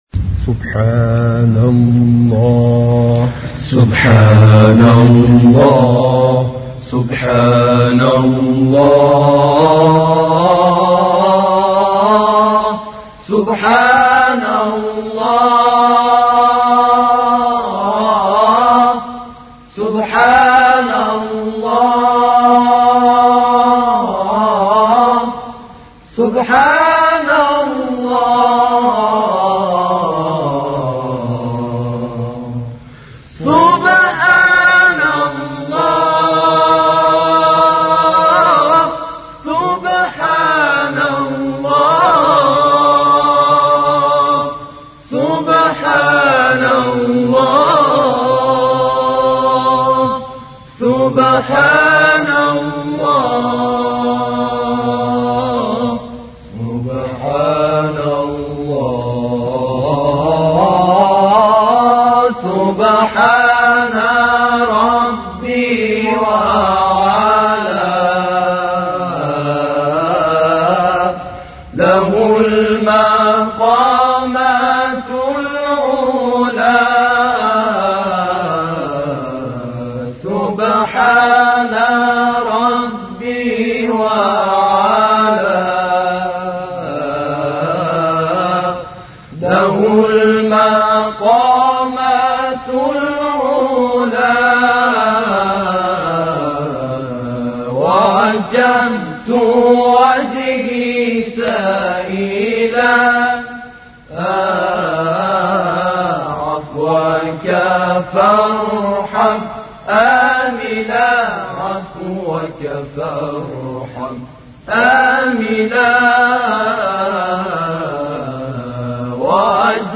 دانلود سبحان الله، سبحان الله، سبحان الله سخنران : حجم فایل : 2 مگابایت زمان : 11 دقیقه توضیحات : موضوعات : دسته بندی ها تواشیح عربی